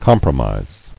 [com·pro·mise]